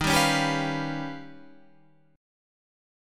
D# Augmented 9th